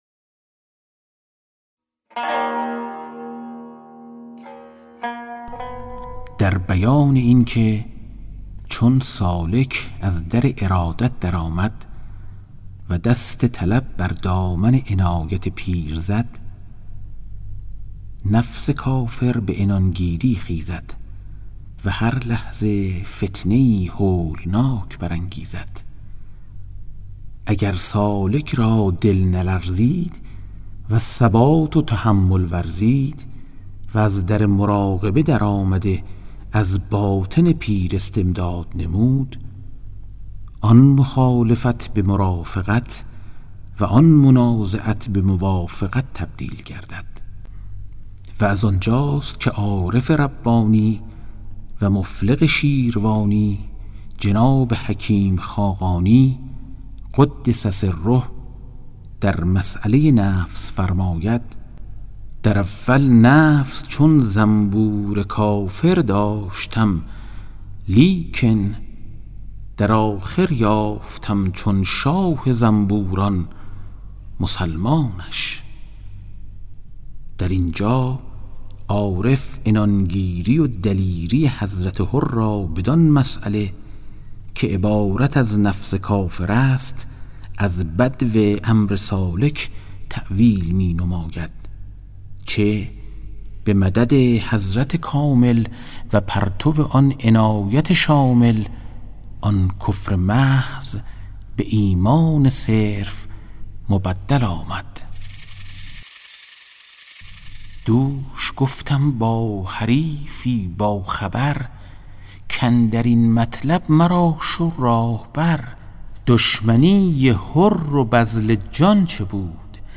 کتاب صوتی گنجینه‌الاسرار، مثنوی عرفانی و حماسی در روایت حادثه عاشورا است که برای اولین‌بار و به‌صورت کامل در بیش از 40 قطعه در فایلی صوتی در اختیار دوستداران ادبیات عاشورایی قرار گرفته است.